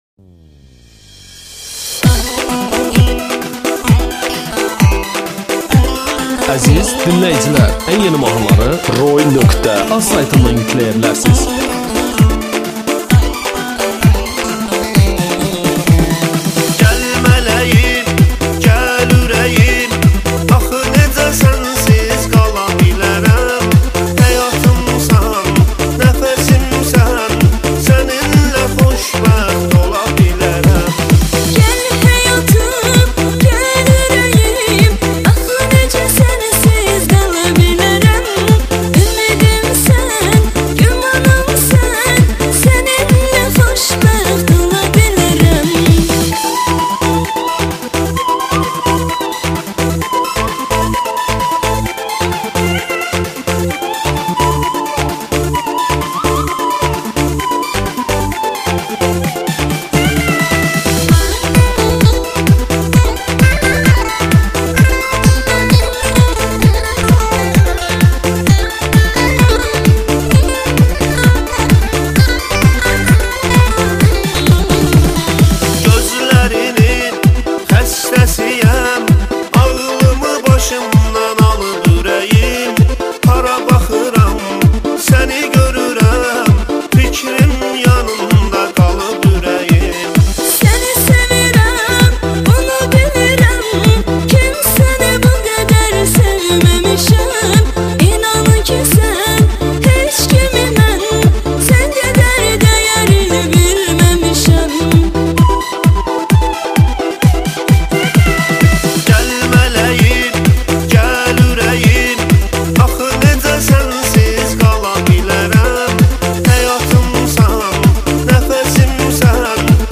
meyxana